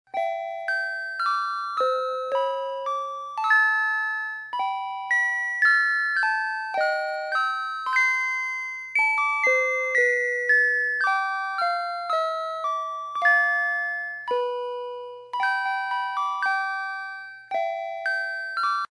電子オルゴール関係
音痴版 (オクターブダウン)